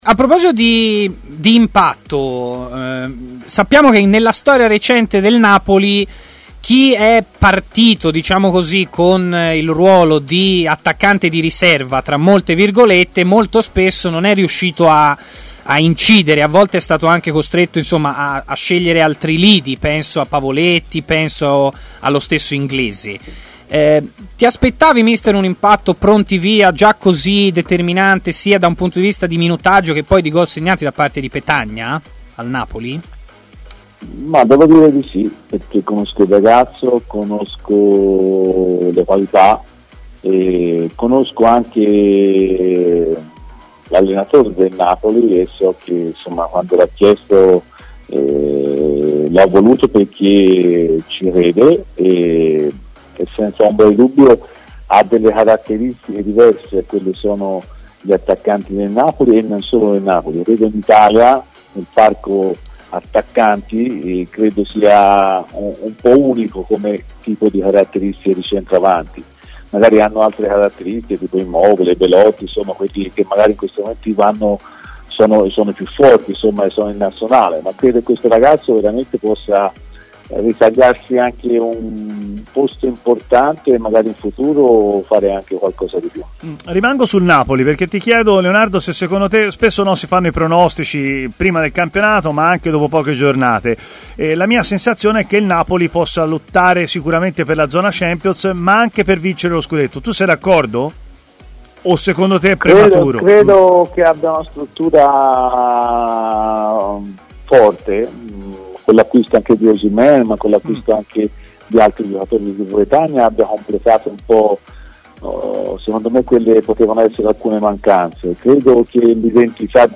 L'allenatore Leonardo Semplici è intervenuto in diretta a TMW Radio, nel corso della trasmissione Stadio Aperto.